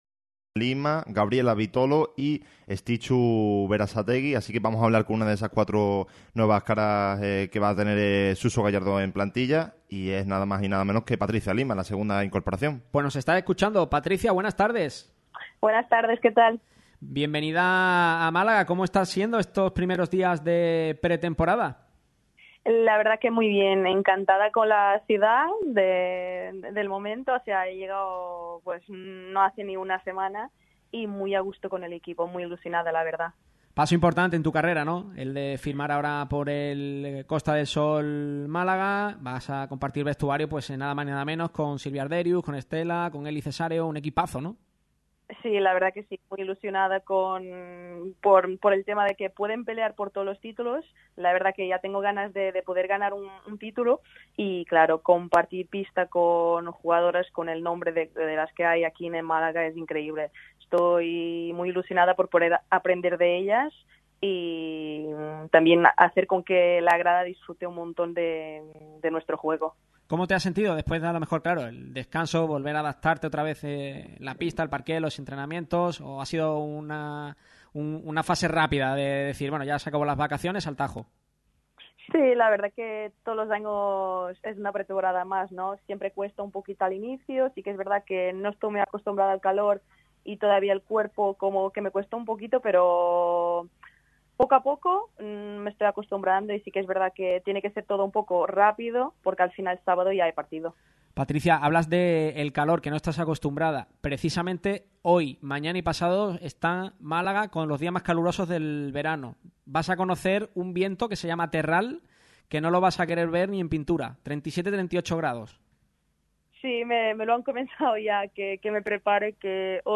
ha realizado una entrevista para Radio Marca Málaga en la que asegura que «aspira a ganar títulos aqui«. La central internacional con Portugal proviene del Mecalia Atlético Guardés.